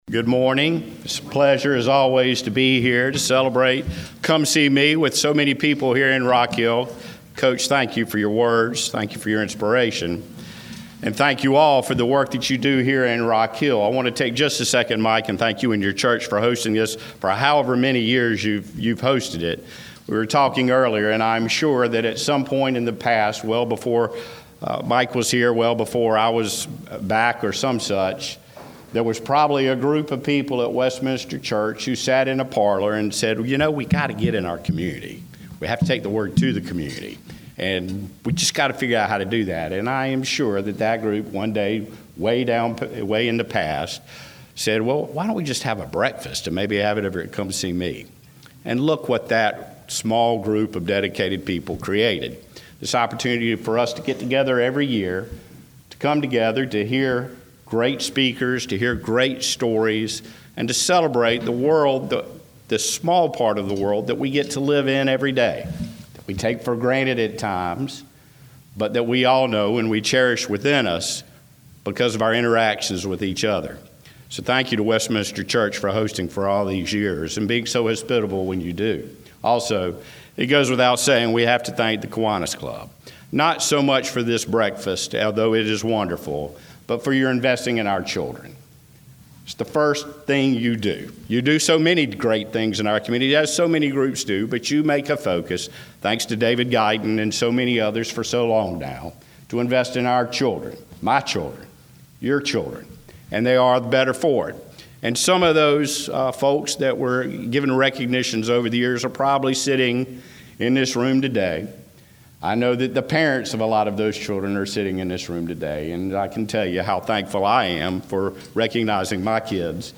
One of the speakers at the Come See Me Kiwanis Mayor’s Prayer Breakfast was none other than Rock Hill Mayor John Gettys who spoke about investing in our children and asked even bigger question.